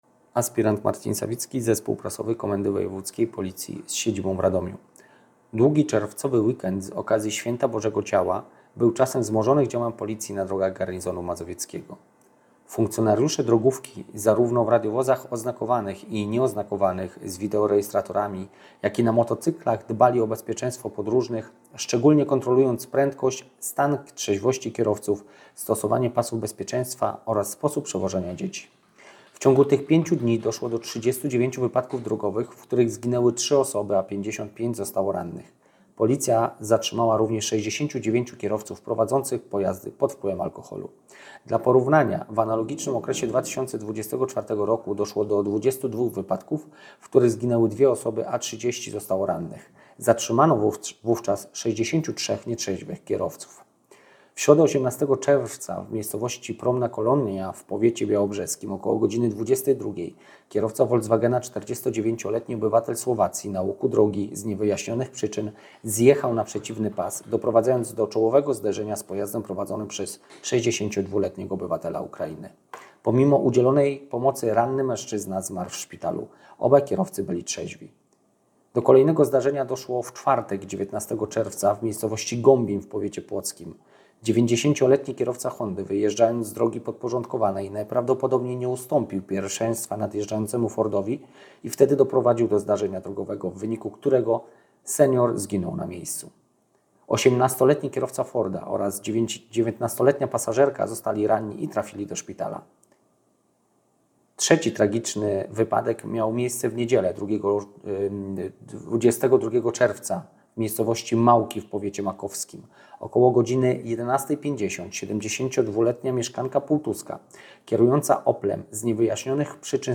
wypowiedź